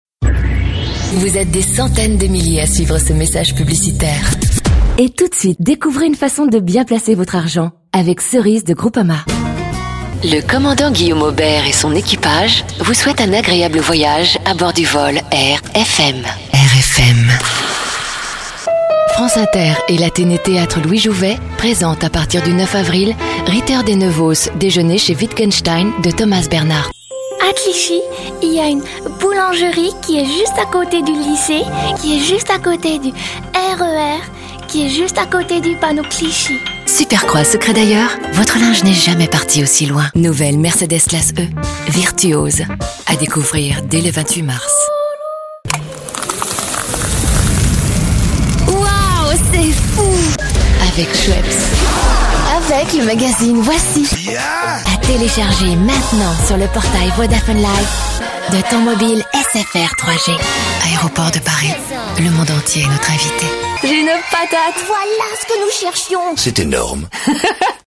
Sprechprobe: Werbung (Muttersprache):
Smooth, professional, trustworthy or sweet, her voice plays in versatile ranges: Colorful, honest, silky, warm, alluring & deep, Parisian..